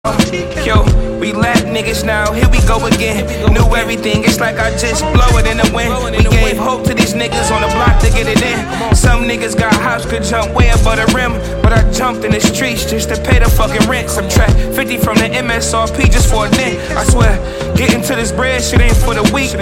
sticking to the boom-bap sound